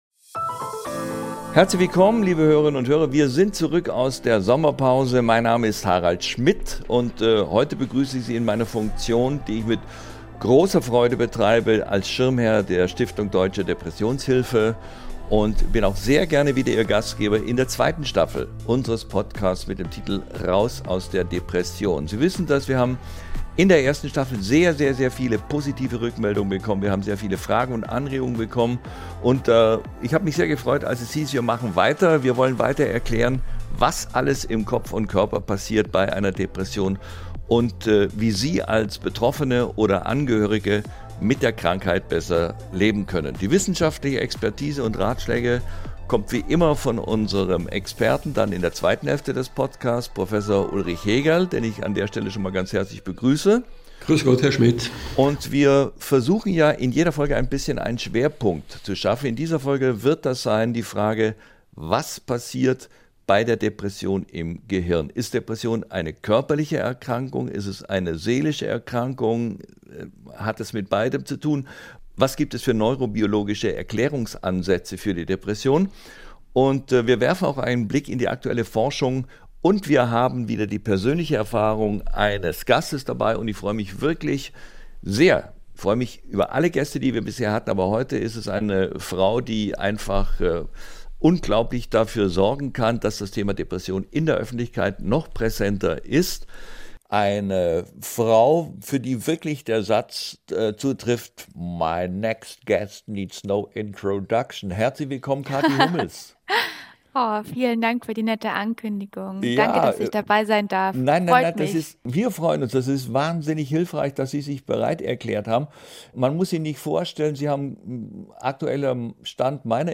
Cathy Hummels erzählt Harald Schmidt, wie sie schon als Teenager zunächst gemobbt wurde und dann an Depressionen erkrankte, ohne es zu wissen.